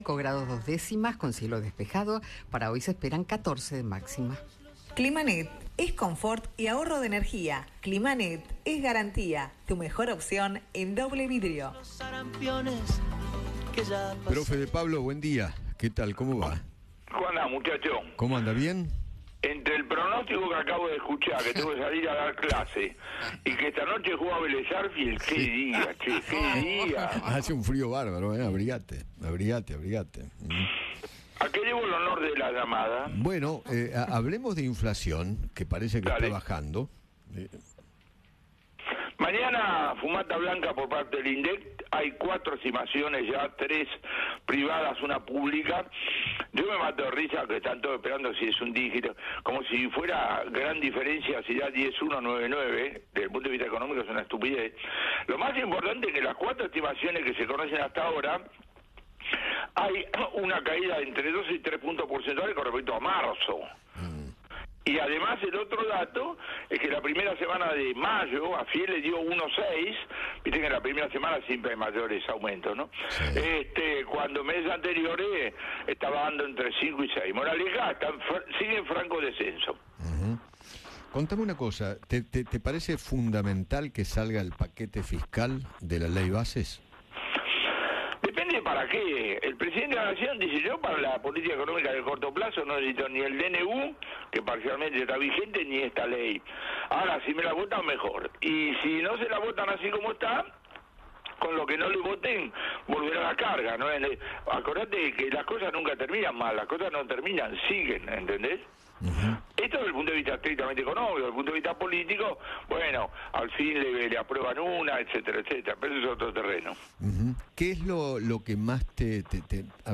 El economista Juan Carlos De Pablo conversó con Eduardo Feinmann y analizó las cuatro estimaciones sobre la inflación de abril que espera el Gobierno de Javier Milei.